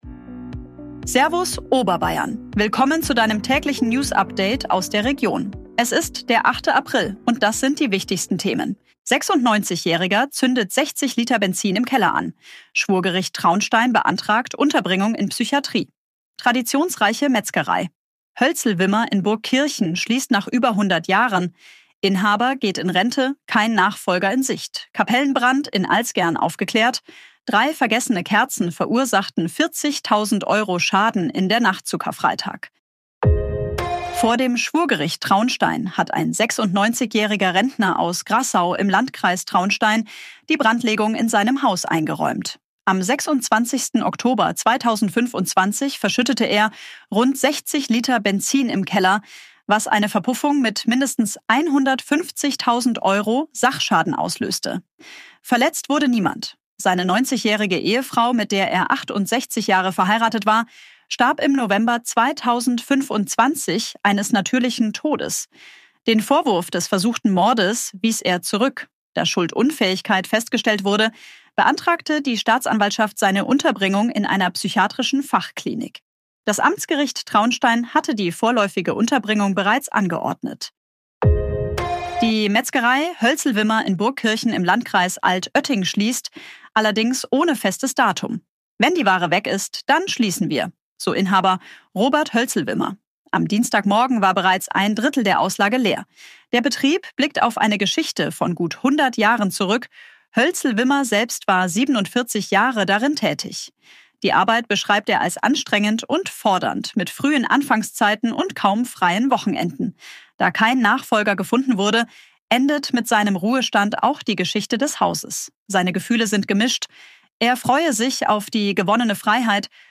Tägliche Nachrichten aus deiner Region
Dein tägliches News-Update